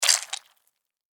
squish.mp3